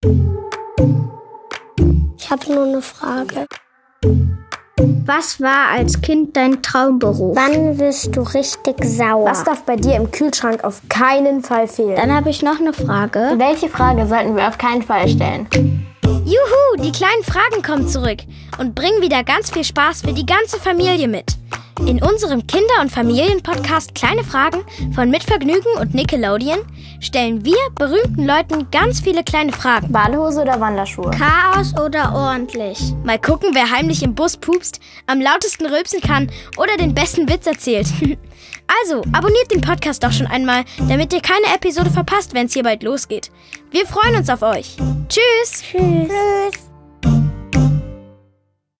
Hier treffen kleine Moderator*innen auf einen Gaststar und stellen ihre lustigen, kreativen und manchmal auch frechen Fragen.